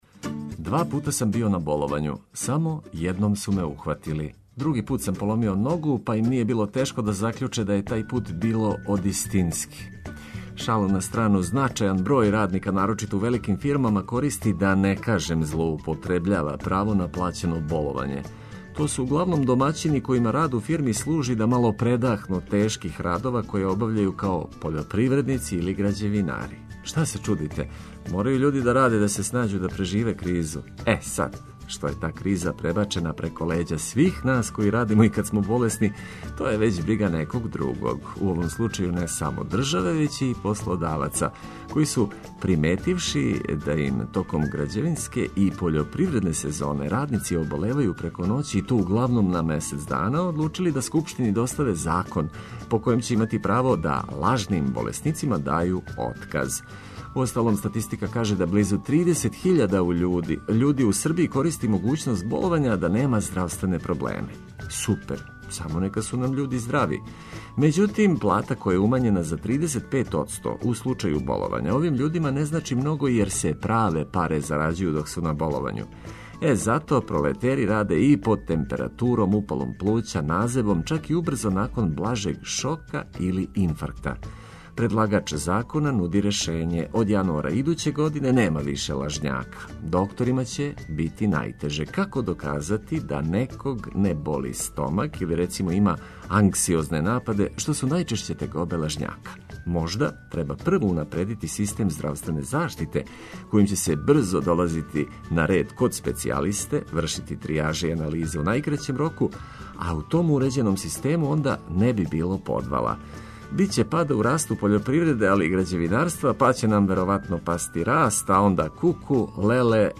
Сазнајте све што је важно док се разбуђујете уз тактове сјајне музике.